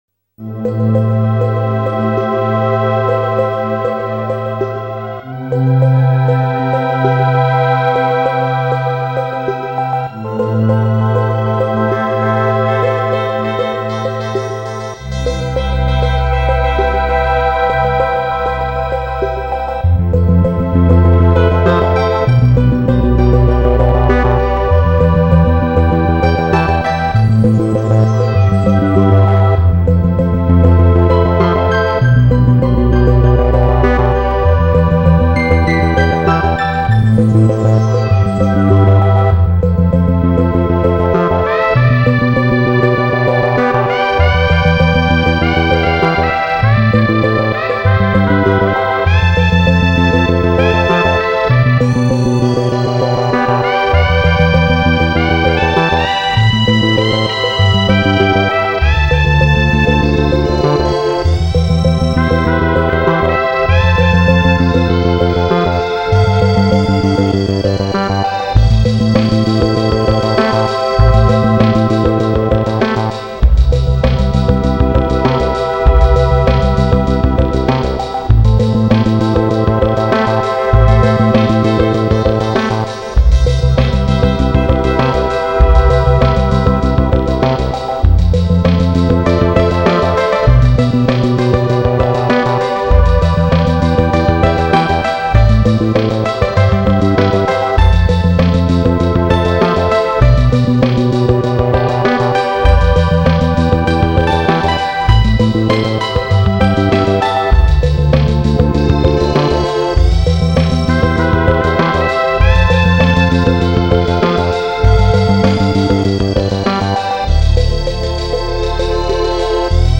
BPM99-99
Audio QualityPerfect (Low Quality)